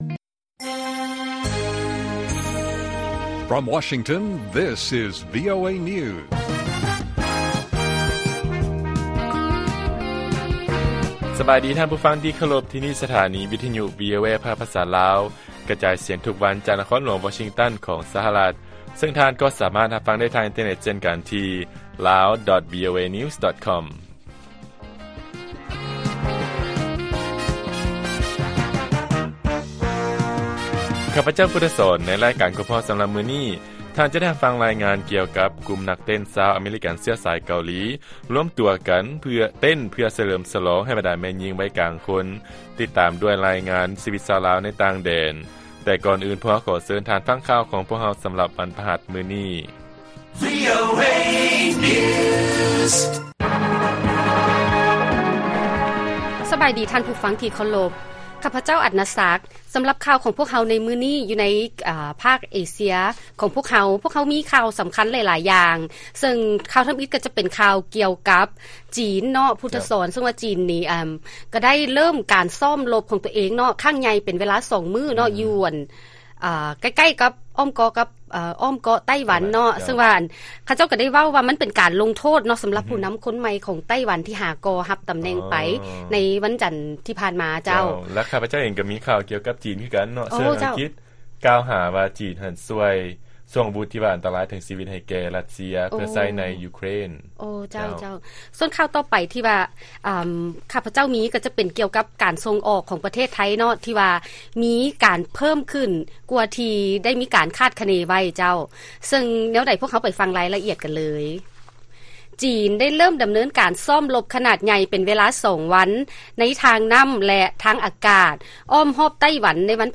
ລາຍການກະຈາຍສຽງຂອງວີໂອເອ ລາວ: ບົດສຳພາດພິເສດ